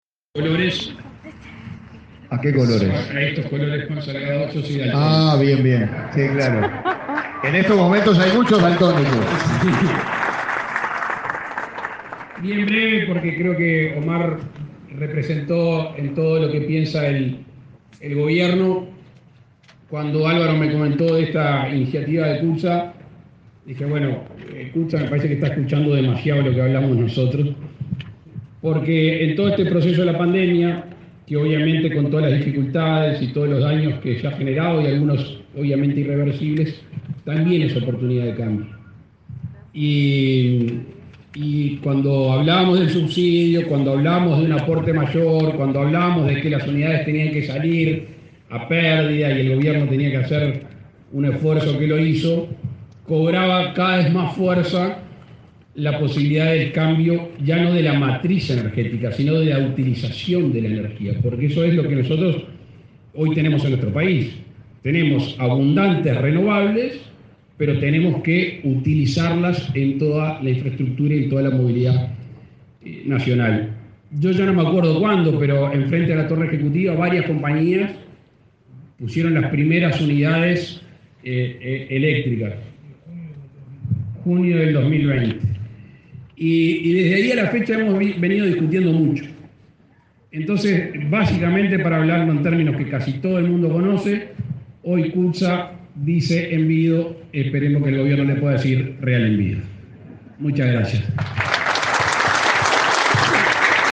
Palabras del presidente de la República, Luis Lacalle Pou
El presidente de la República, Luis Lacalle Pou, participó este 8 de marzo en el lanzamiento del cambio de la matriz energética y monitoreo del nivel